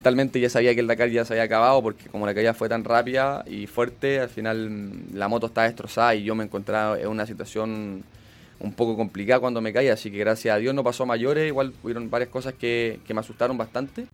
A tres semanas del hecho, Barbosa entregó nuevos detalles en entrevista con Radio Bío Bío en Concepción.